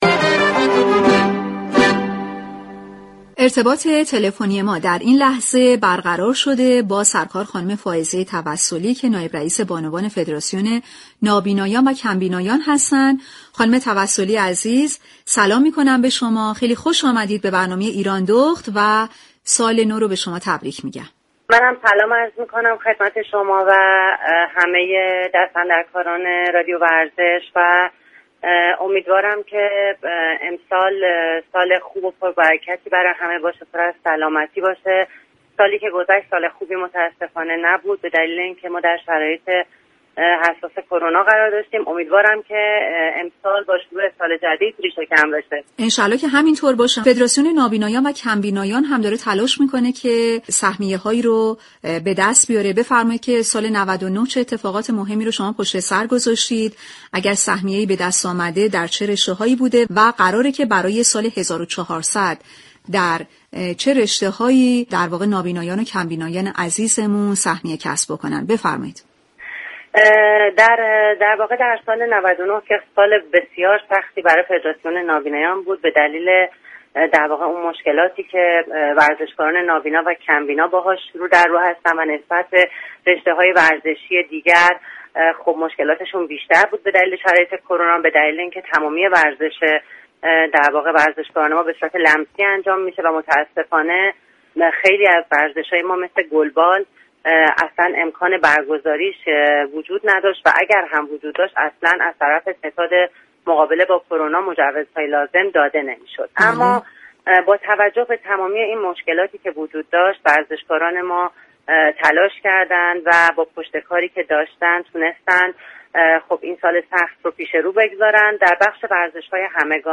ویژه برنامه نوروزی«ایرانداخت» به مصاحبه با بانوان افتخارآفرین ورزش ایران می پردازد.